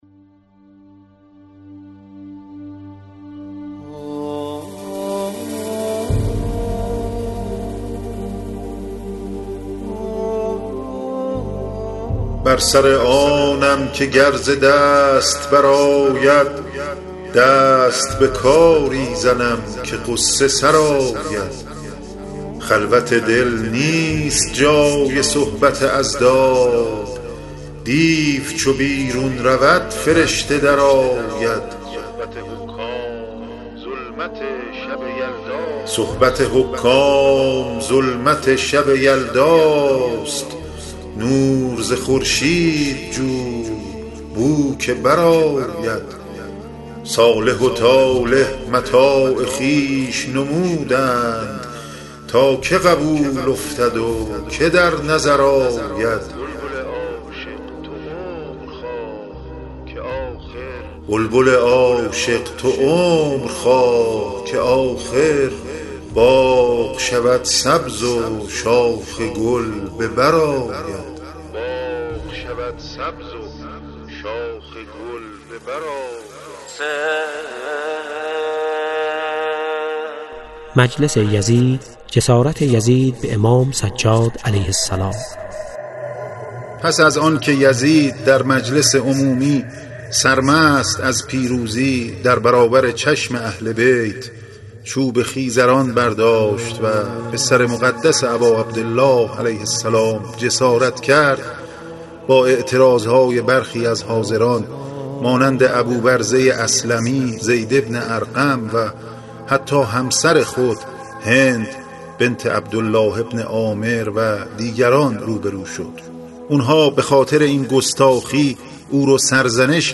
تنظیم و میکس: التجا